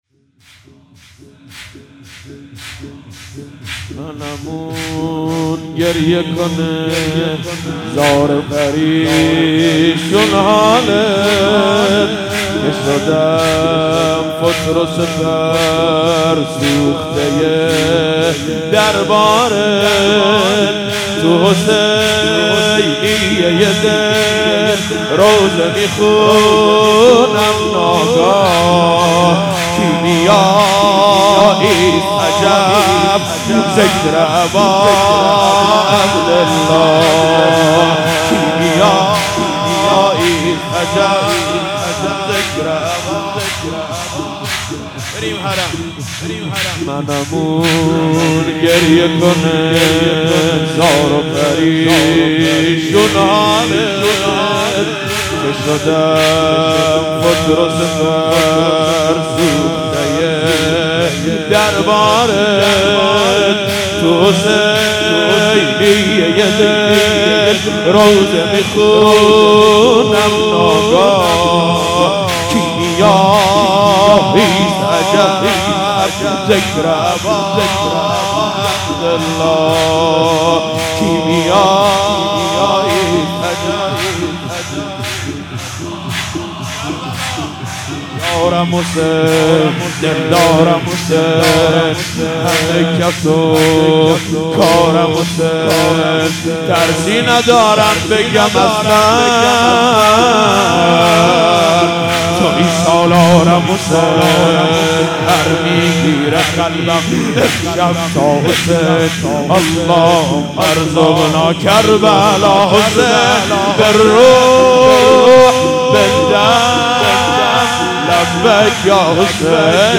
مراسم هفتگی/6دی97